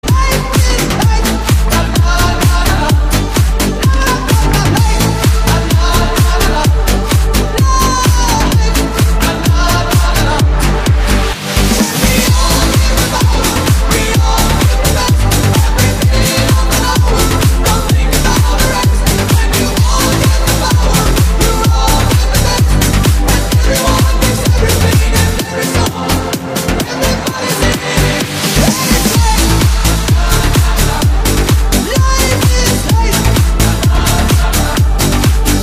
• Качество: 192, Stereo
dance
для поднятия настроения